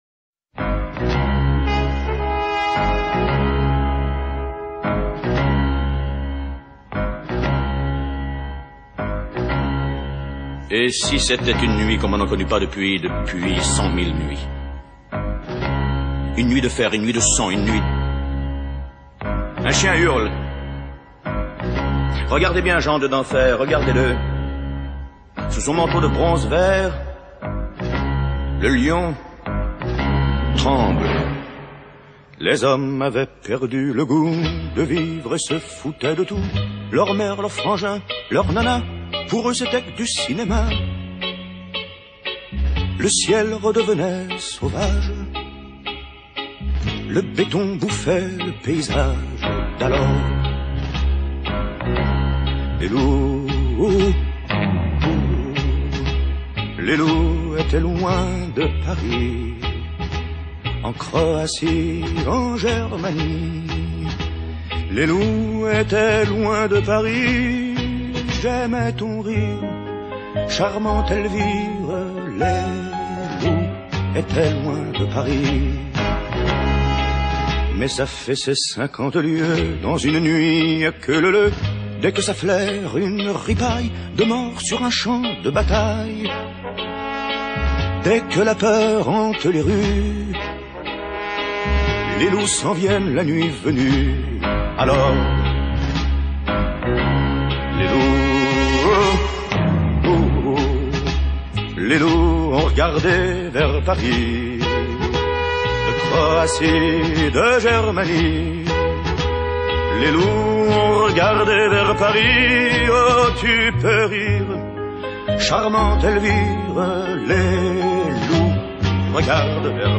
sombre chanson